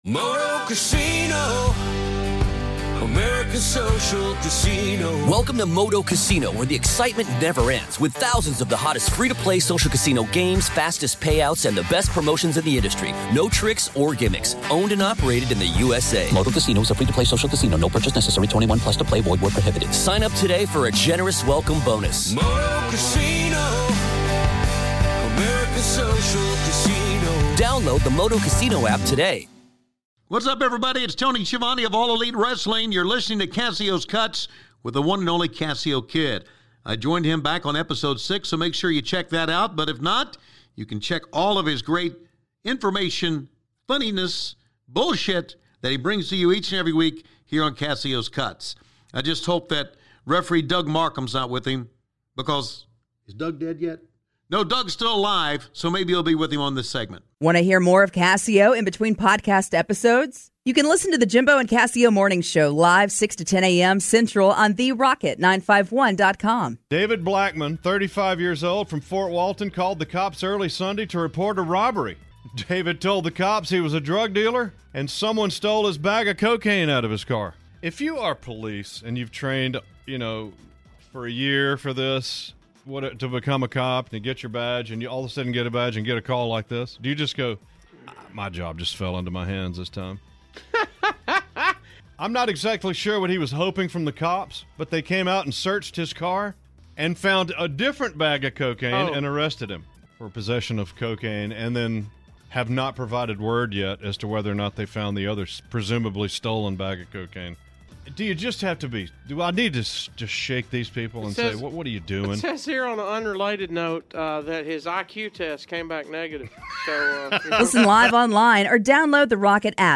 These commercial reads were hilarious, plus spoof commercials thrown in.